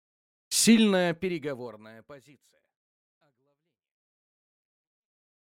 Аудиокнига Сильная переговорная позиция | Библиотека аудиокниг